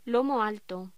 Locución: Lomo alto
voz